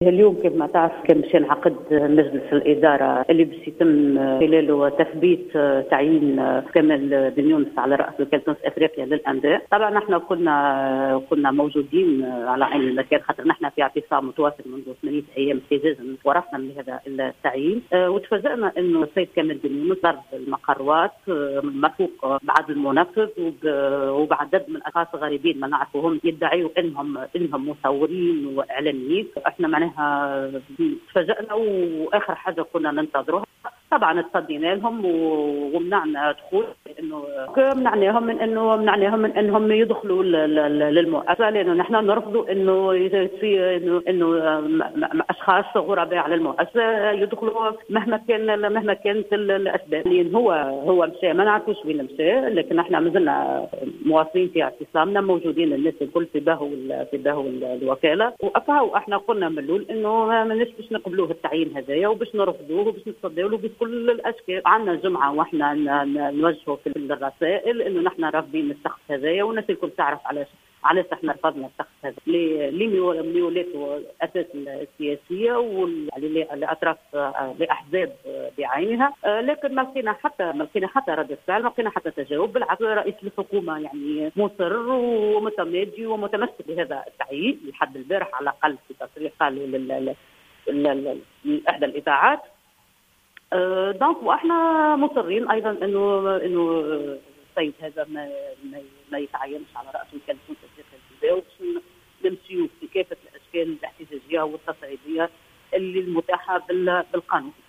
في تصريح لـ"الجوهرة أف أم"